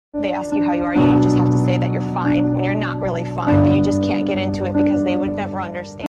Womp Womp Sound Effects Free Download